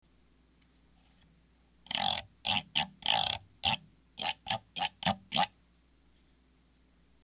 ダンボールの畝の穴に串が刺してあり、並んだ串を、串でこすります。